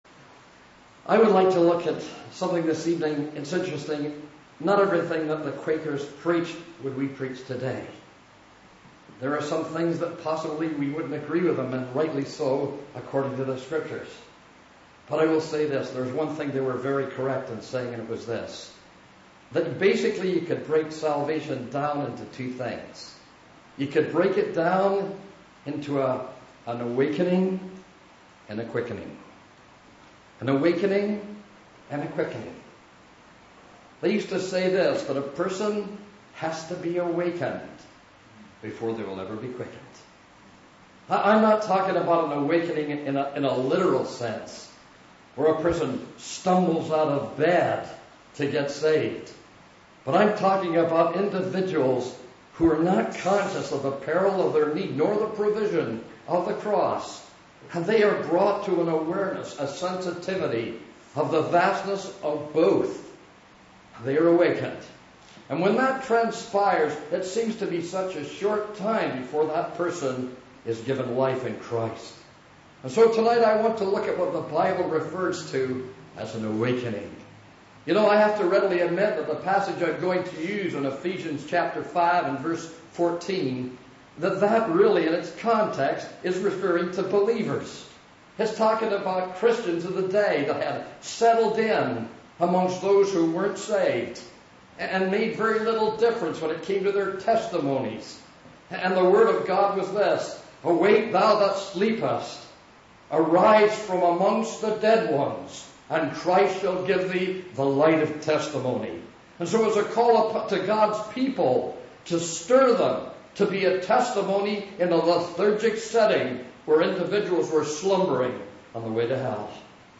Wake Up! – Gospel Message (38 mins)